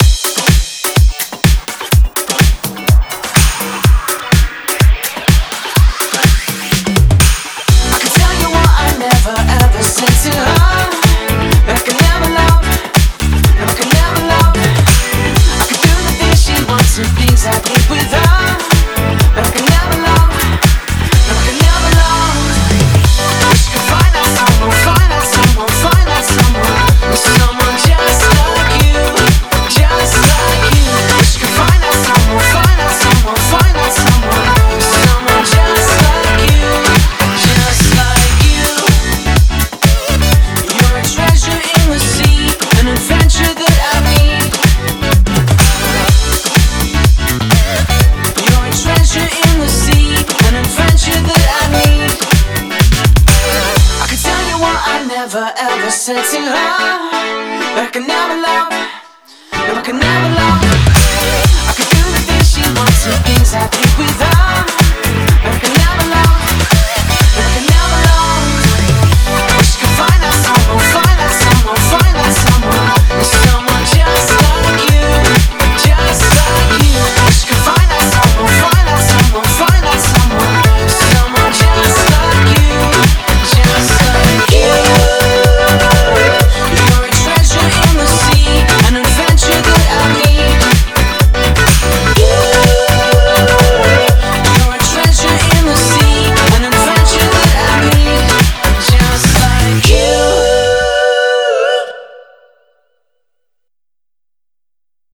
BPM125
MP3 QualityMusic Cut